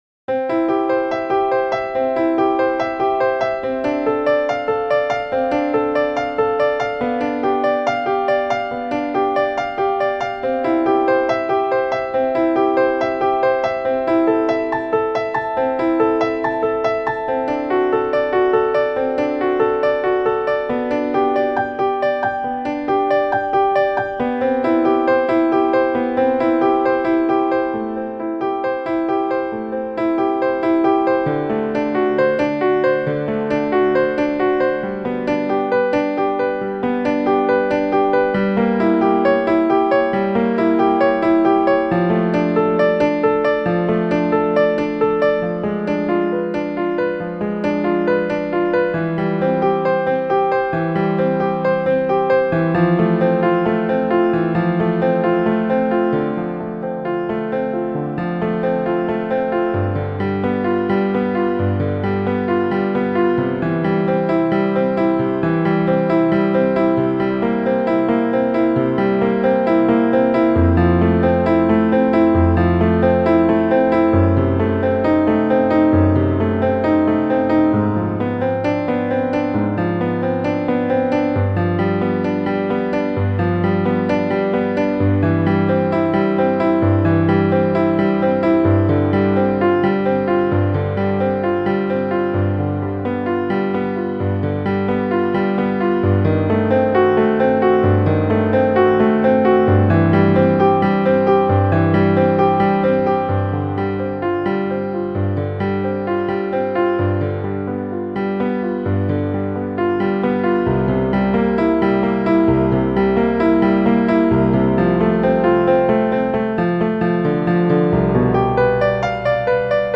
Listen to the Prelude in C Major here: You can download this as an MP3 here .